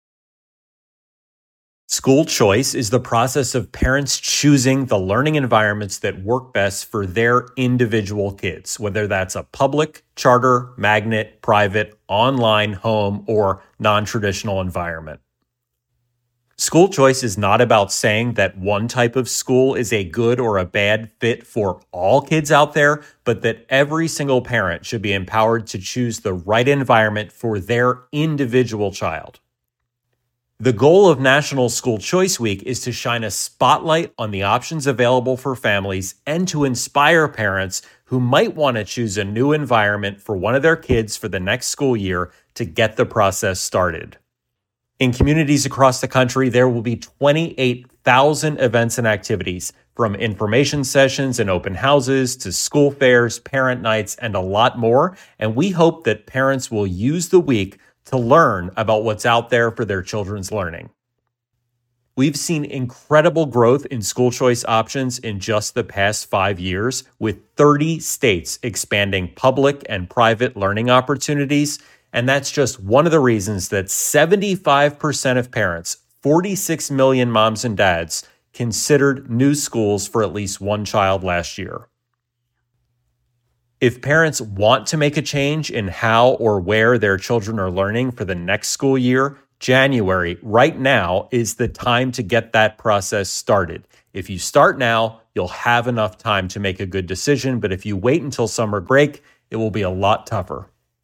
Audio Sound Bites for Radio News